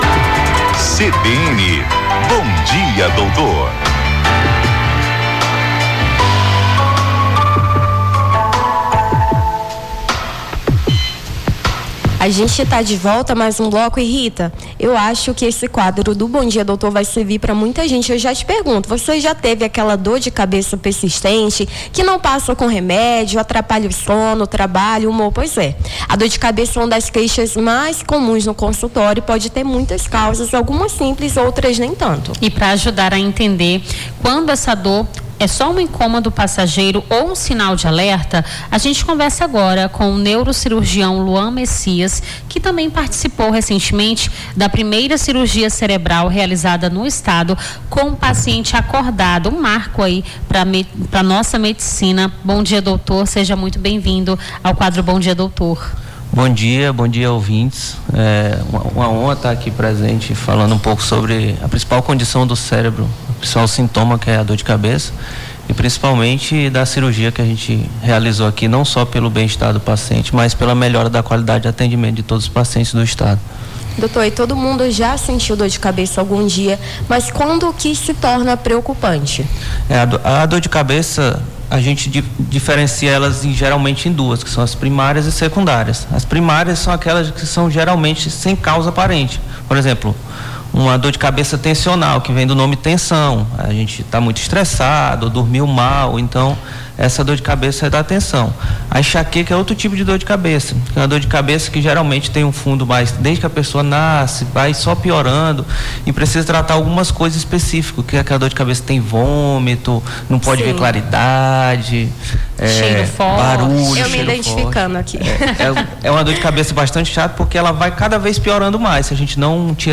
as apresentadoras
conversaram com o neurocirurgião